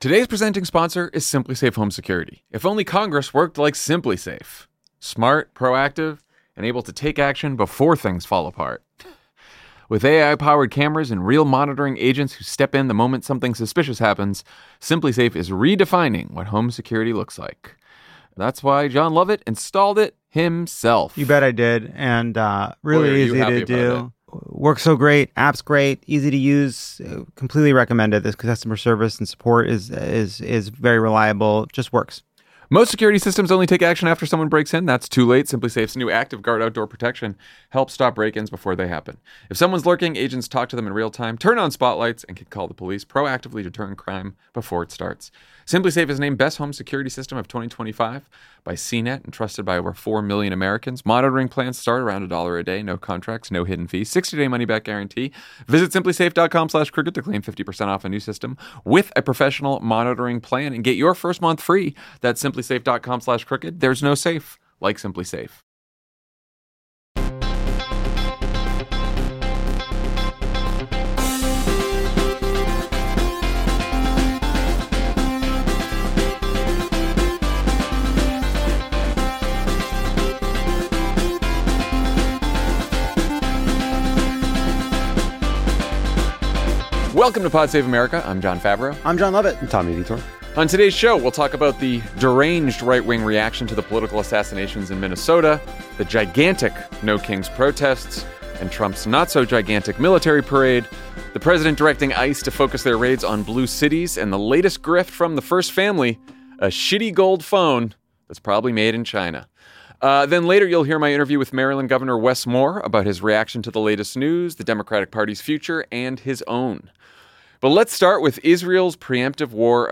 Jon, Lovett, and Tommy discuss the weekend's competing optics, the deeply troubling rise of political violence in America, and the latest offering from the Trump family hucksters: a shiny gold smartphone from the newly founded Trump Mobile.